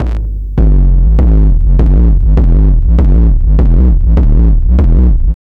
HD BD 17  -L.wav